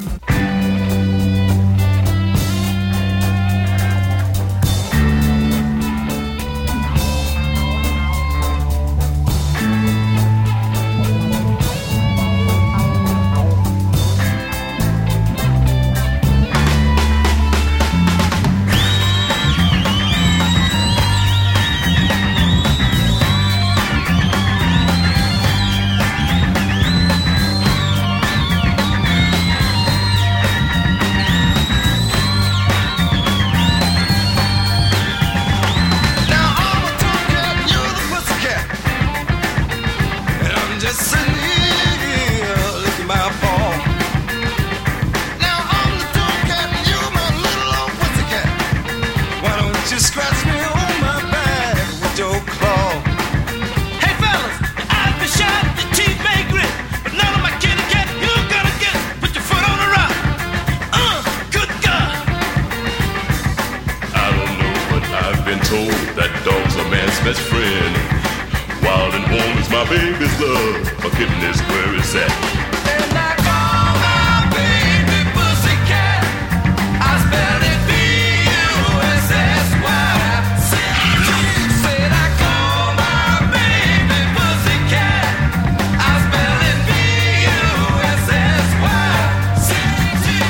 [ FUNK | SOUL ]